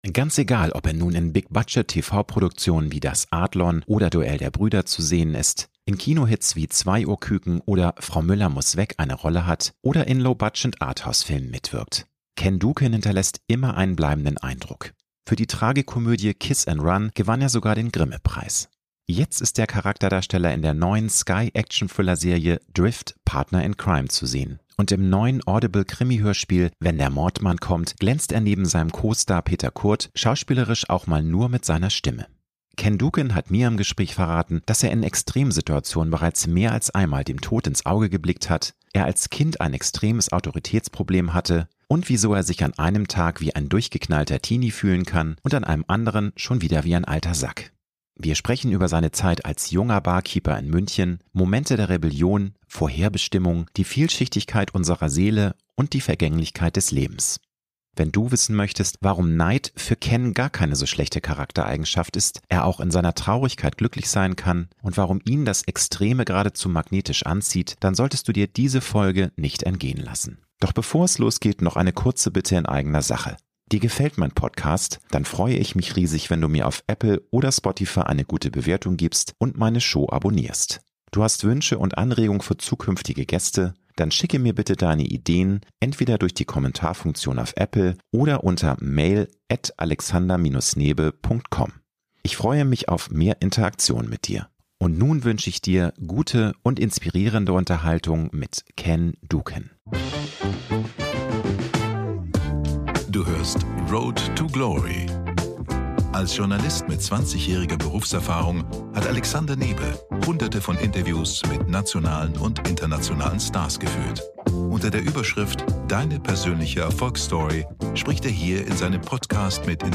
Ich wünsche dir gute und inspirierende Unterhaltung mit Ken Duken!